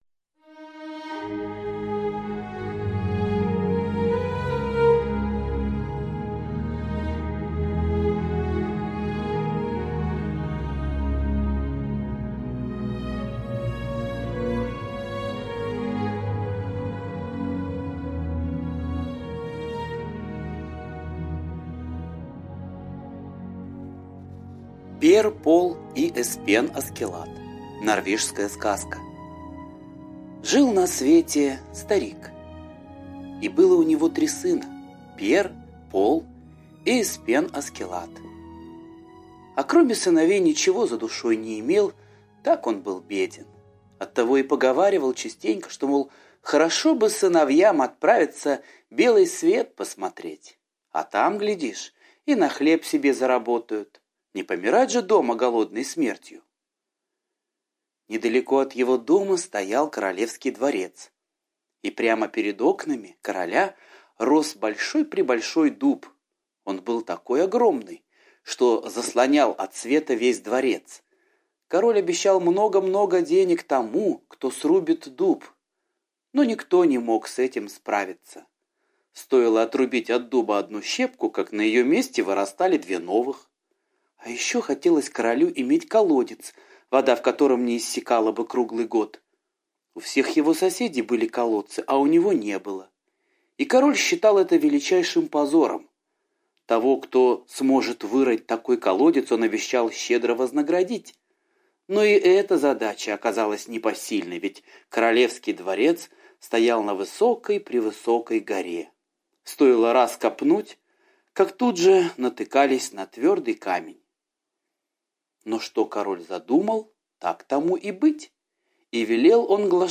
Пер, Пол и Эспен Аскеллад - норвежская аудиосказка - слушать онлайн